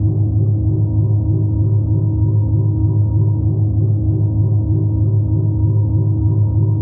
sci-fi_forcefield_hum_loop_10.wav